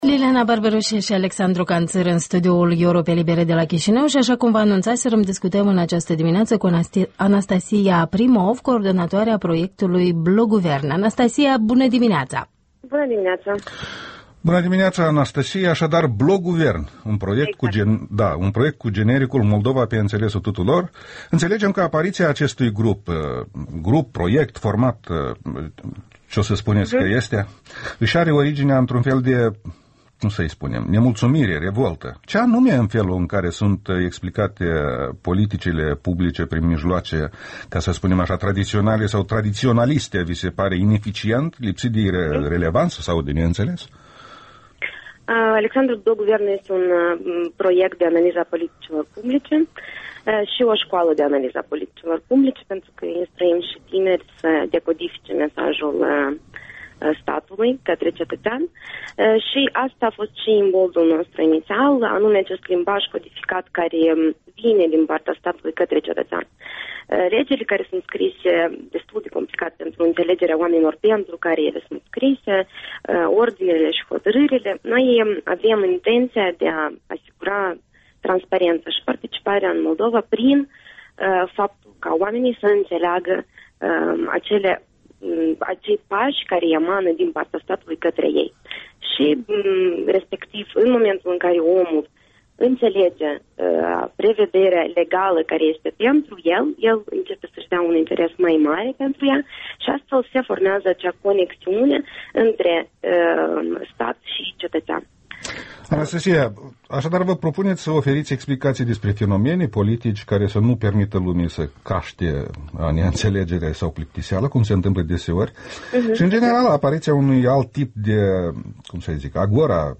Interviul dimineţii